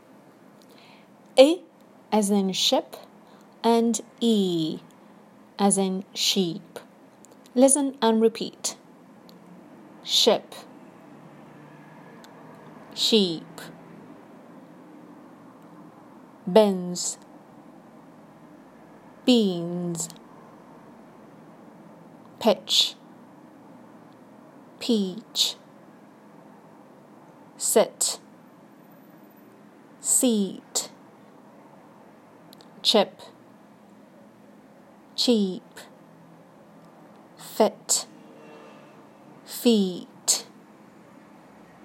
This means that each pair of words has the same pronunciation except for 1 sound /ɪ/ or /i:/.